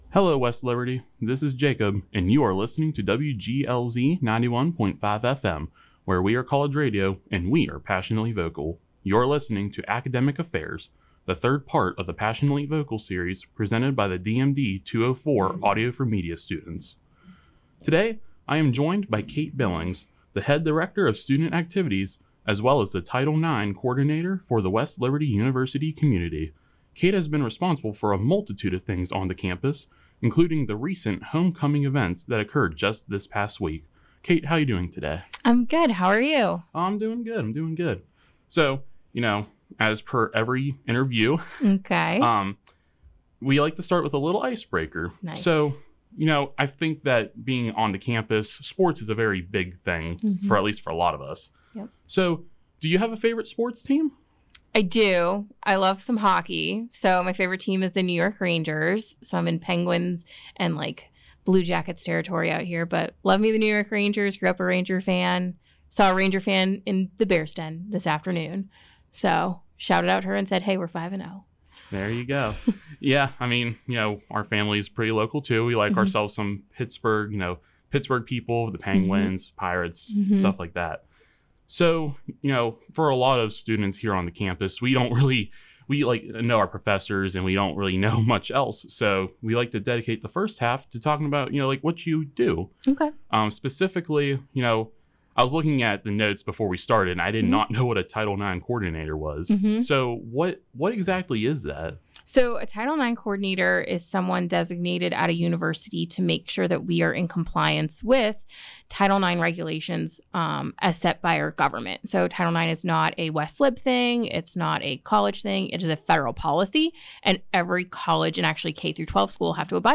In the first half of the interview